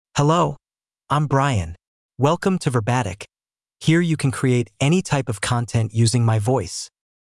MaleEnglish (United States)
BrianMale English AI voice
Brian is a male AI voice for English (United States).
Voice sample
Listen to Brian's male English voice.
Brian delivers clear pronunciation with authentic United States English intonation, making your content sound professionally produced.